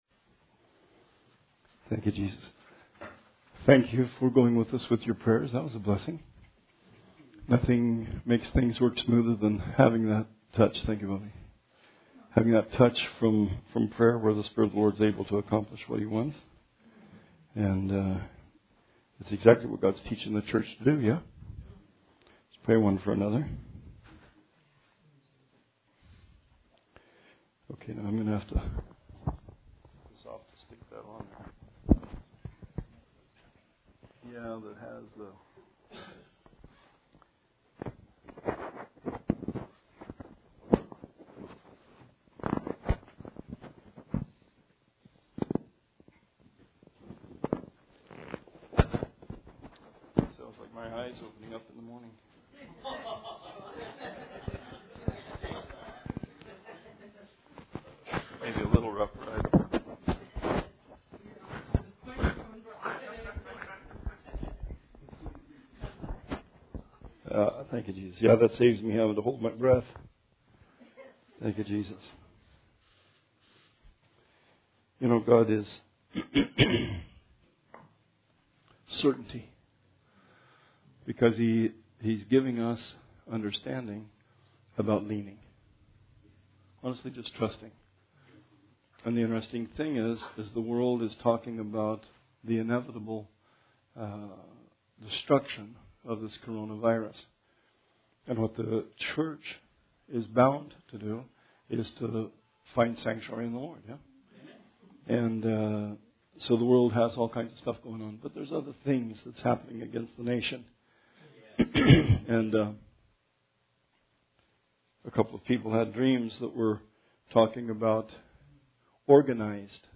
Bible Study 3/11/20